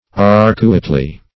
arcuately - definition of arcuately - synonyms, pronunciation, spelling from Free Dictionary Search Result for " arcuately" : The Collaborative International Dictionary of English v.0.48: Arcuately \Arc"u*ate*ly\, adv.